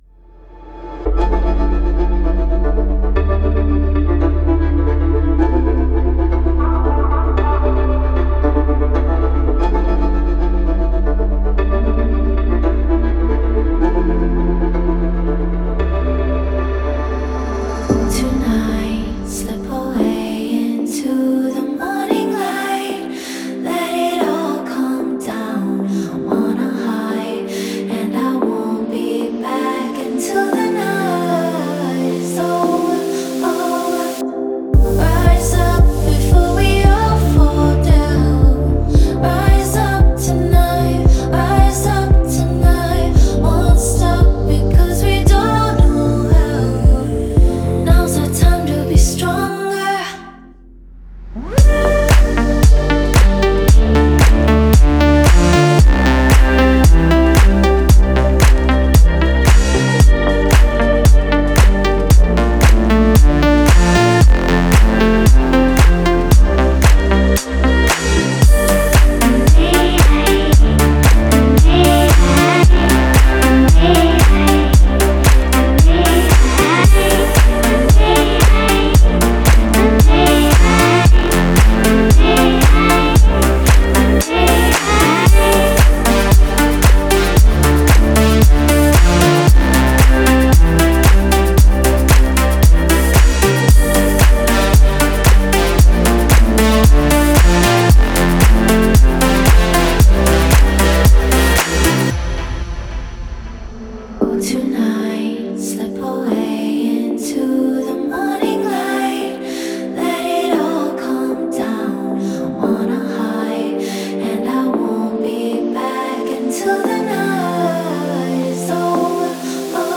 энергичная электронная танцевальная композиция
Сильный вокал и динамичное звучание